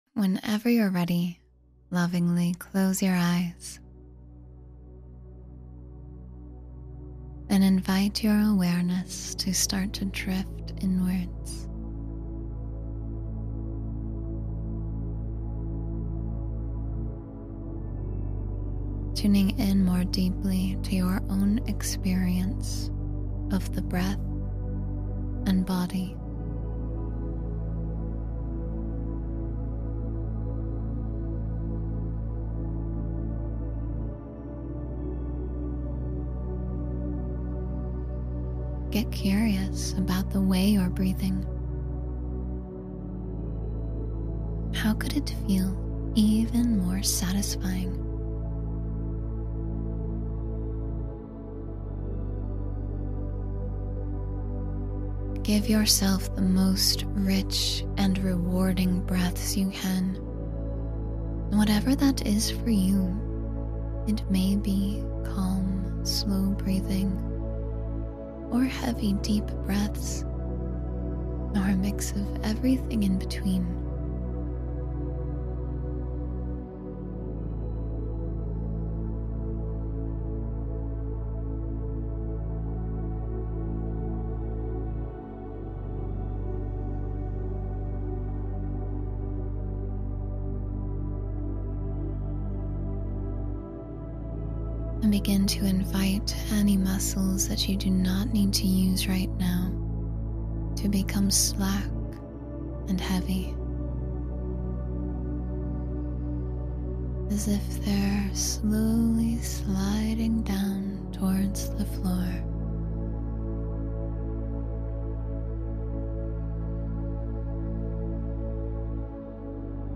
Begin Again—Your New Chapter Starts Now — Meditation for Fresh Starts and Hope